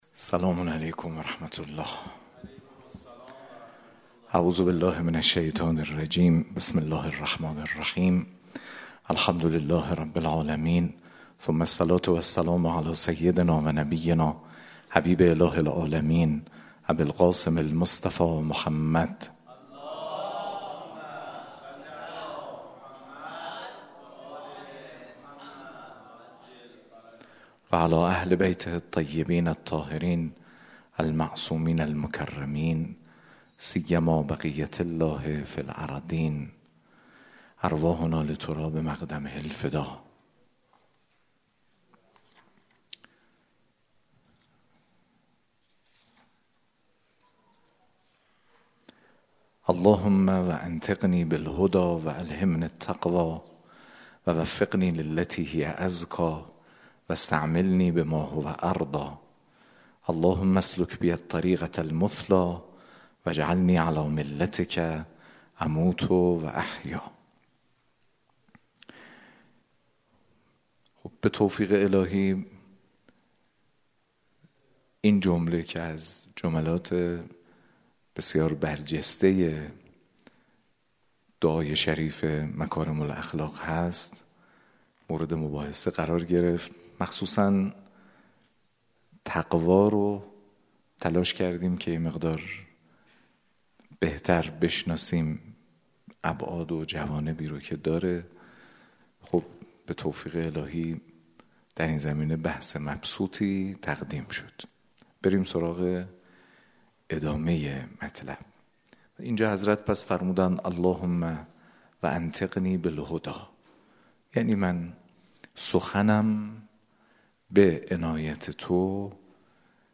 به گزارش ایکنا، جلسه شرح صحیفه سجادیه با سخنان حجت‌الاسلام والمسلمین محمدجواد حاج علی‌اکبری؛ رئیس شورای سیاست‌گذاری ائمه جمعه، طبق روال هر هفته، شامگاه دوشنبه 13 آذر در مجموعه فرهنگی سرچشمه برگزار شد که گزیده مباحث آن را در ادامه می‌خوانید؛